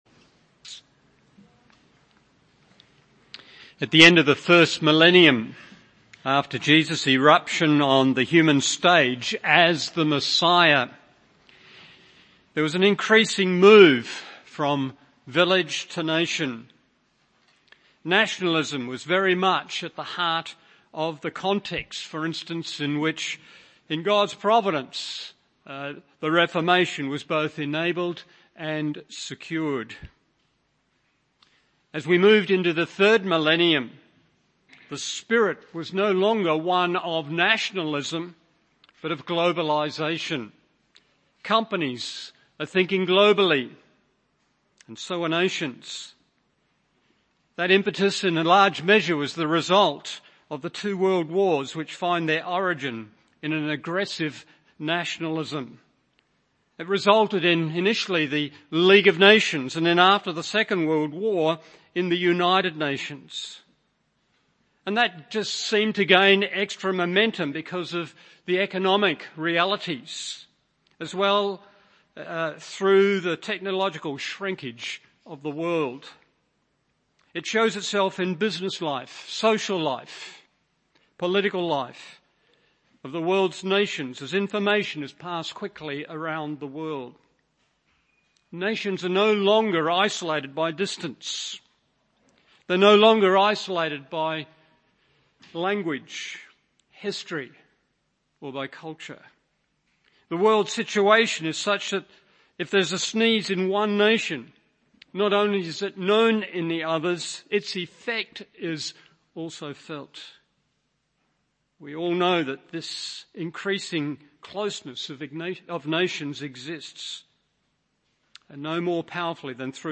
Morning Service Genesis 10 1. The Common Origin of the Nations 2. The Common Hope of the Nations 3. The True Hope of the Nations…